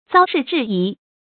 遭事制宜 注音： ㄗㄠ ㄕㄧˋ ㄓㄧˋ ㄧˊ 讀音讀法： 意思解釋： 猶言因事制宜。